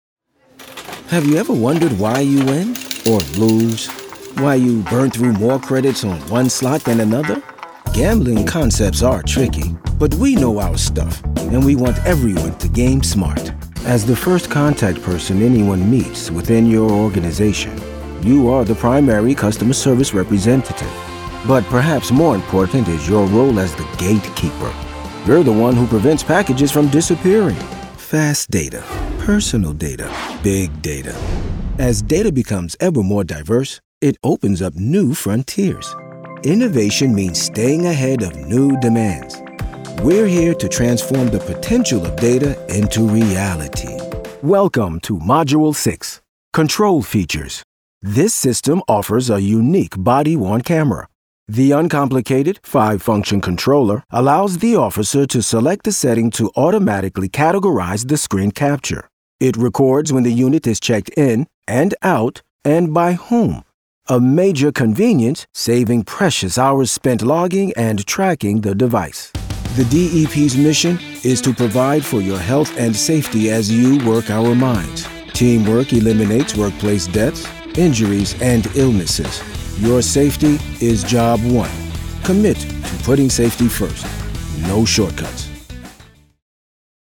Male
Adult (30-50)
friendly, confident, warm, believable, authoritative, sexy, credible,
cool, Generation-x, conversational, textured
E-Learning
All our voice actors have professional broadcast quality recording studios.